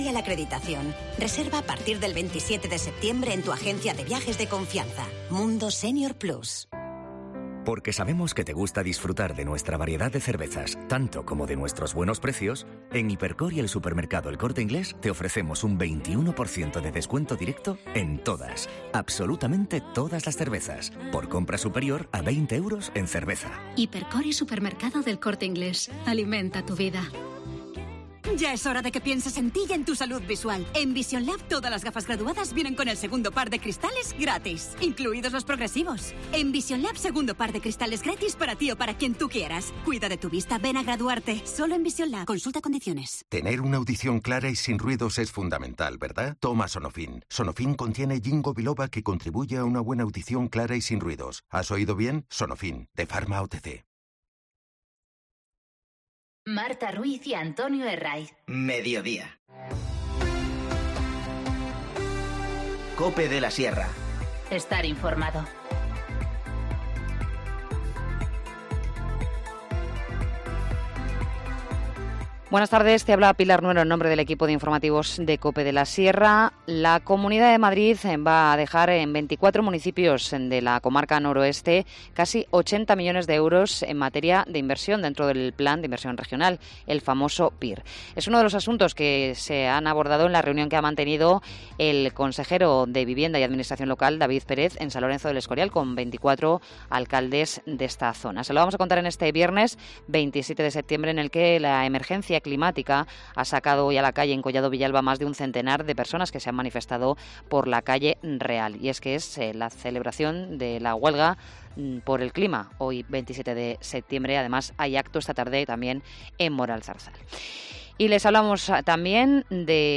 Informativo Mediodía 27 septiembre 14:20h